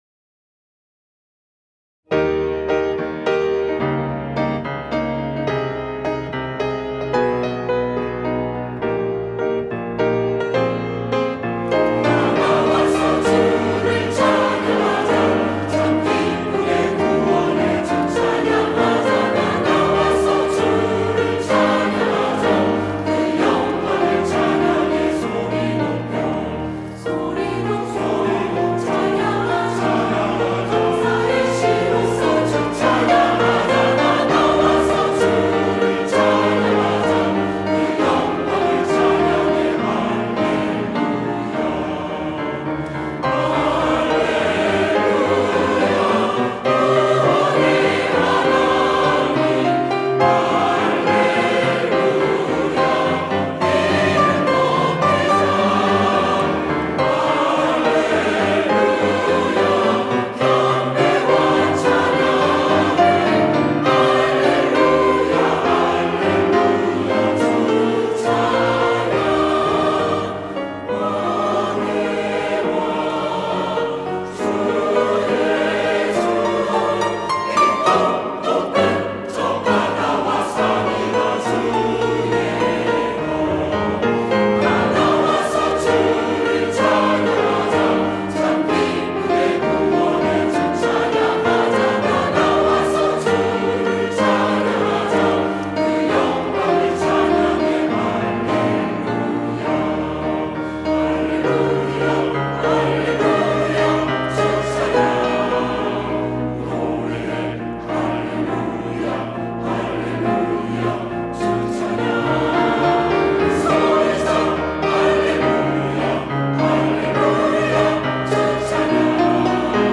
시온(주일1부) - 다 나와서 주를 찬양하자
찬양대